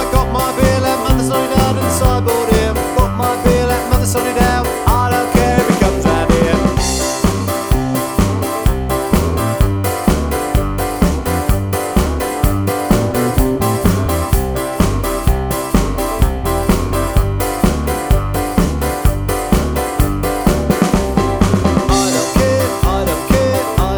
no Backing Vocals Rock 'n' Roll 2:38 Buy £1.50